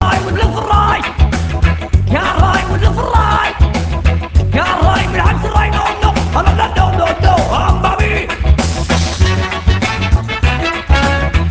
Voix rebelle, enrouée de blues